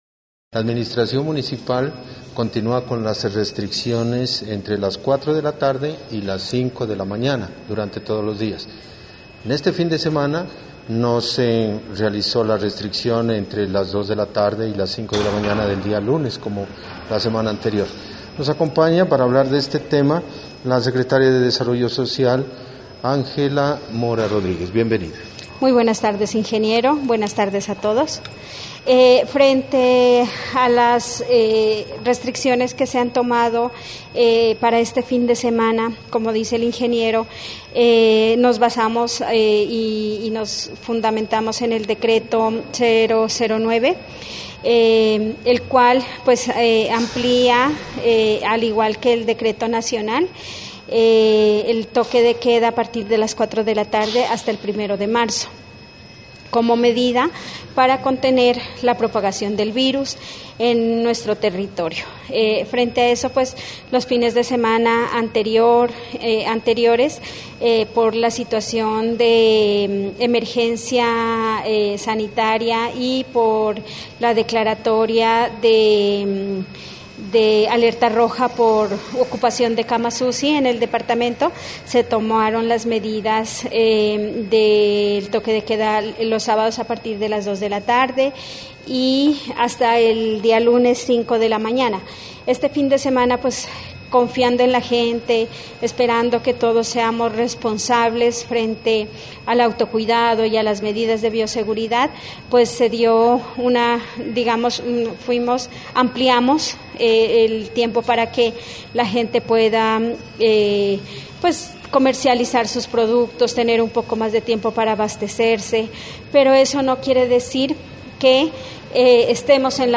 Fue una de las frases que utilizó la secretaria de desarrollo social Ángela Mora Rodríguez en entrevista que realizamos este sábado en la tarde sobre las medidas que tomó la Administración Municipal de sandoná para este fin de semana y sobre la situación causada por el covid-19 en la comunidad.